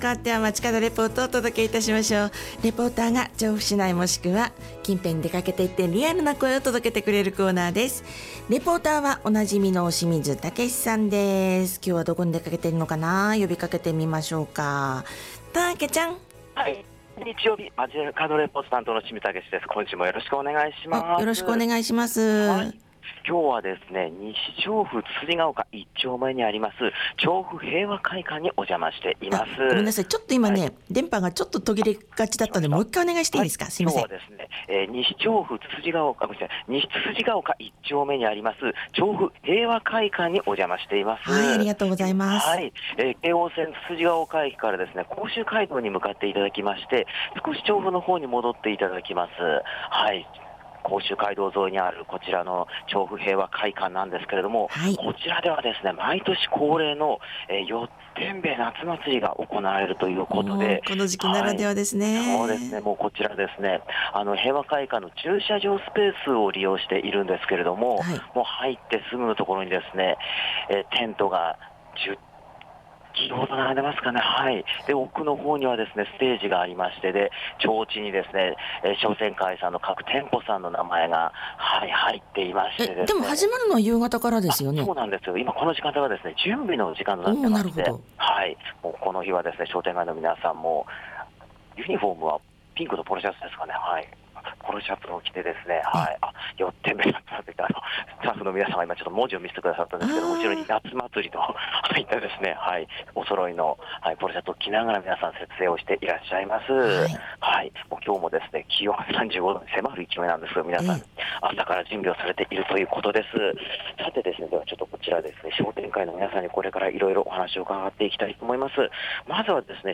雲が多めでそれでも暑い空の下からお届けした街角レポートは、毎年恒例！つつじヶ丘店商店会さんが実施される「よってんべ～つつじヶ丘ふれあい夏祭り2025」からのレポートでした！